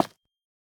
Minecraft Version Minecraft Version 1.21.5 Latest Release | Latest Snapshot 1.21.5 / assets / minecraft / sounds / block / calcite / place3.ogg Compare With Compare With Latest Release | Latest Snapshot